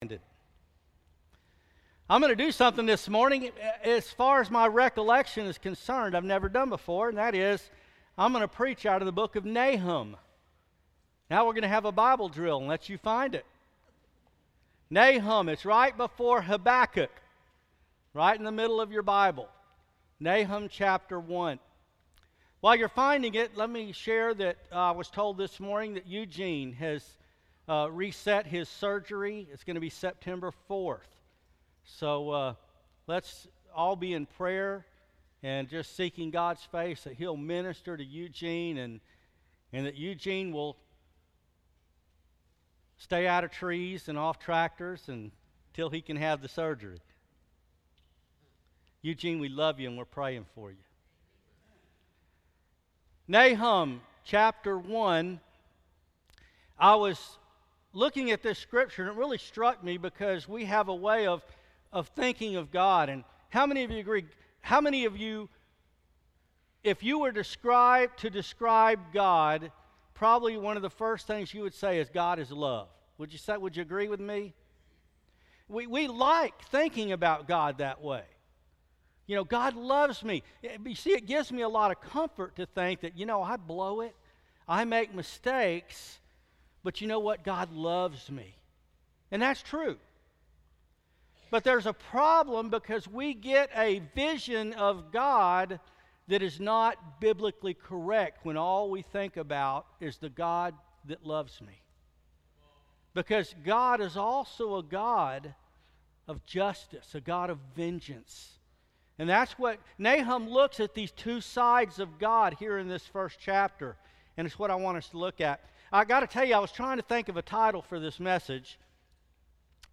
August 2, 2015 The Great and Terrible Passage: Nahum 1:2-7 Service Type: Sunday Morning Worship Bible Text: Nahum 1:2-7 Although God loves us, we cannot lose sight that He is a Great and Terrible God.